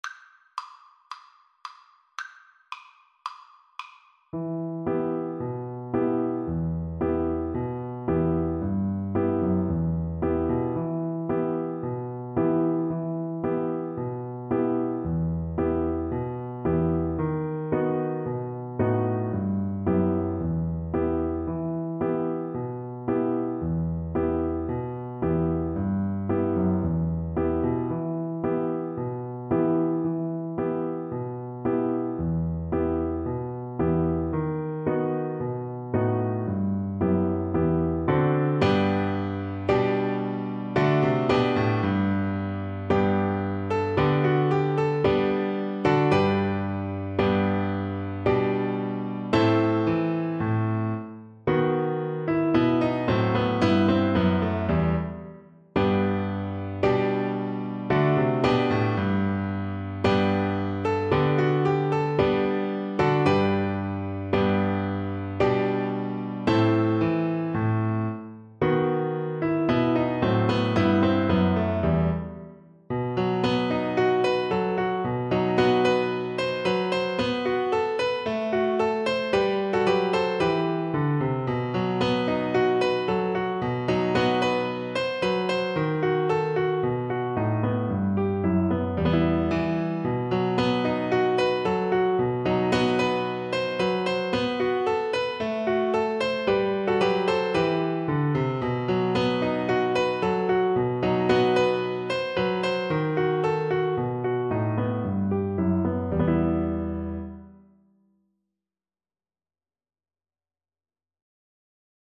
Violin
Traditional Music of unknown author.
4/4 (View more 4/4 Music)
E minor (Sounding Pitch) (View more E minor Music for Violin )
Allegro = 112 (View more music marked Allegro)
Greek